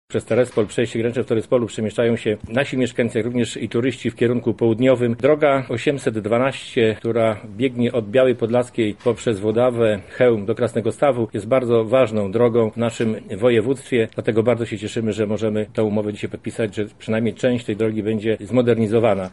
Inwestycja obejmuje także budowę nowego oświetlenia ulicznego.”Odnotowujemy coraz większy ruch ze wschodniej granicy”- mówi Zdzisław Szwed, członek Zarządu Województwa Lubelskiego: